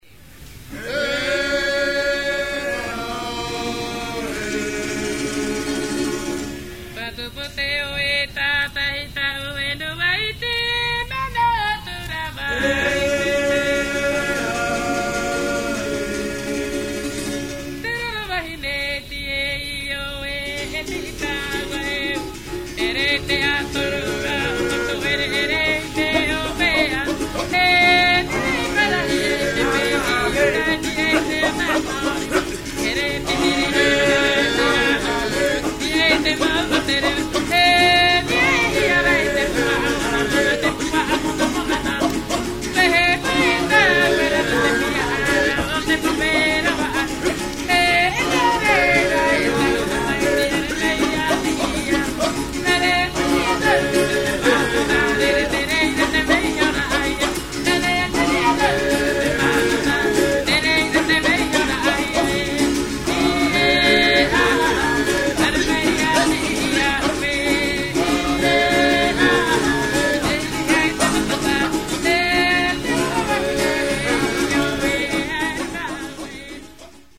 UKÁZKY TAHITSKÝCH PÍSNÍ VE FORMÁTU MP3: